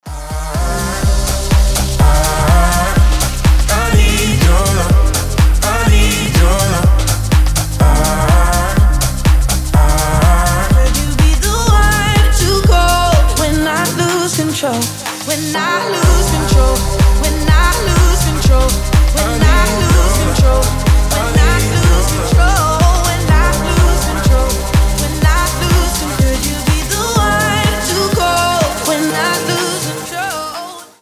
громкие
EDM
дуэт
house